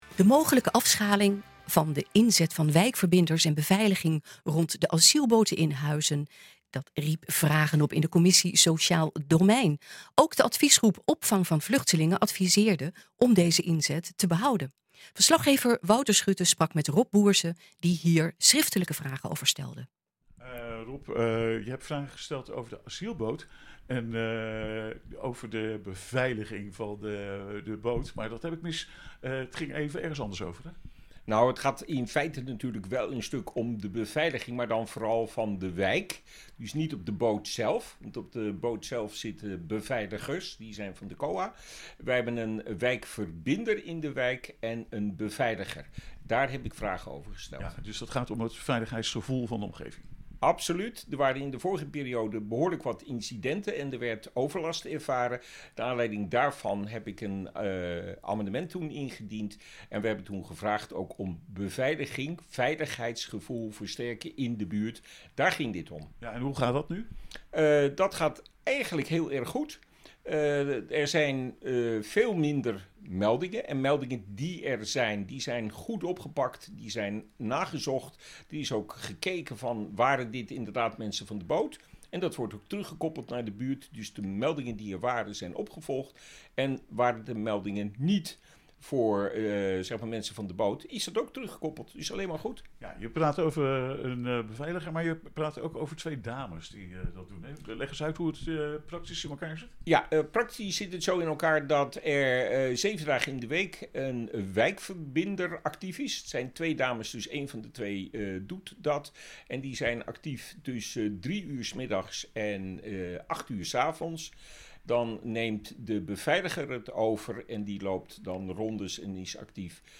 sprak met Rob Bource, die hier schrifftelijke vragen over stelde.